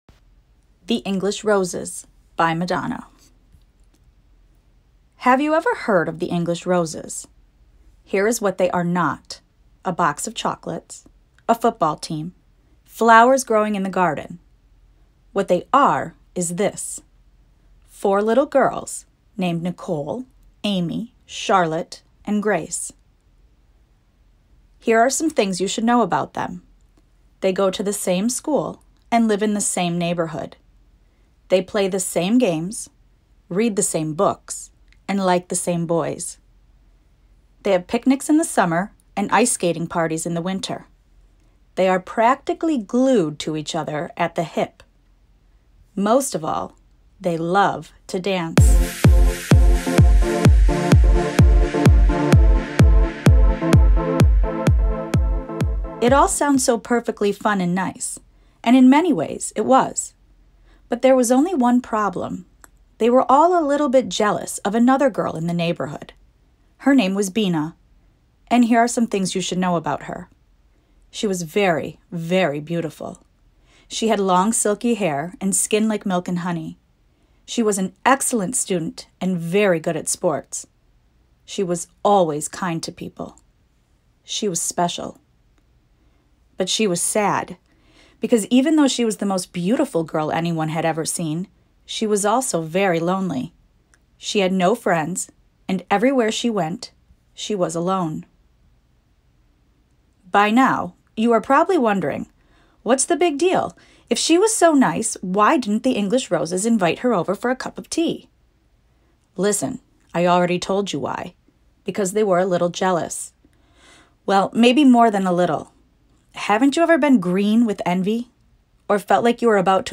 The English Roses by Madonna Read Aloud.mp3